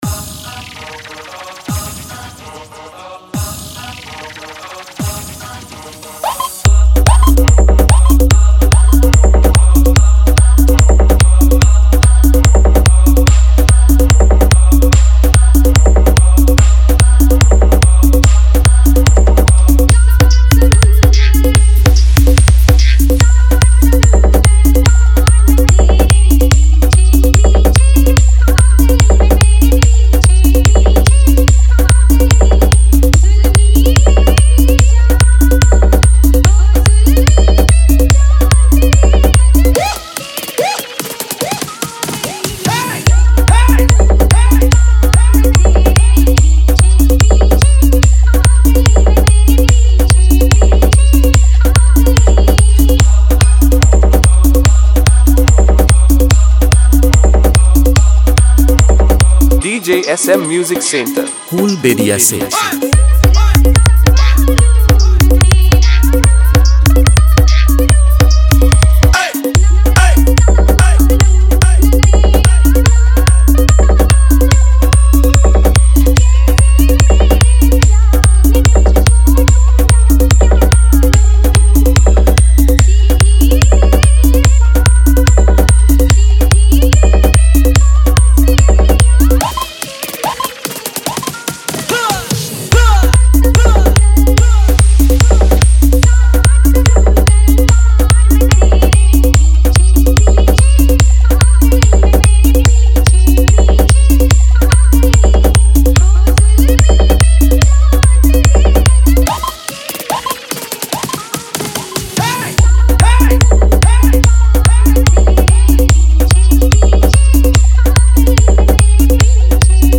Hindi Humming Matal Dance Dhamaka Mix